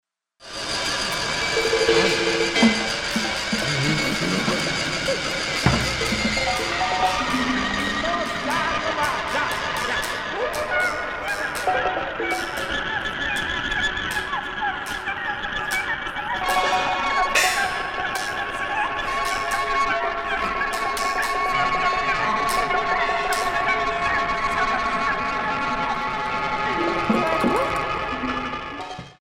with singing and instrumental music.
marimba, percussion
piano, synthesizer, amadinda